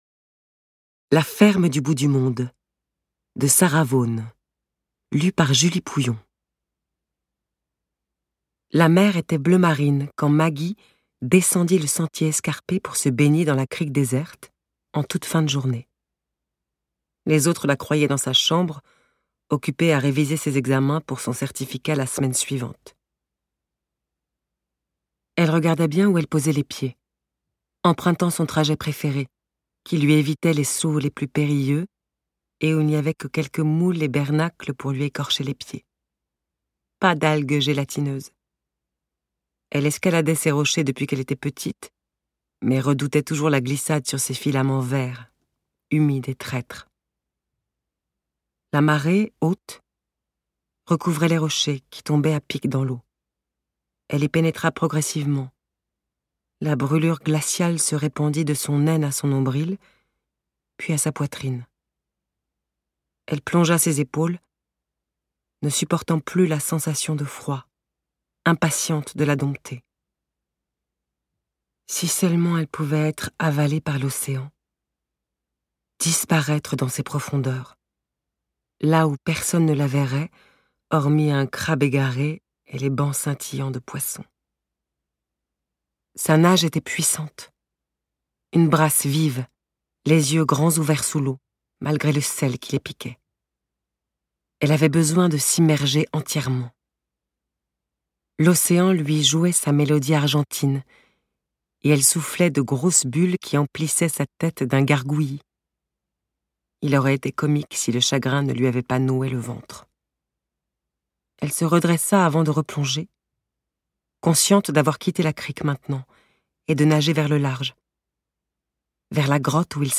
ajoute à cette dimension de confidence avec une voix douce et chaleureuse.